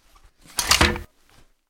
rpgReload.ogg